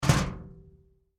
trashmetal4.wav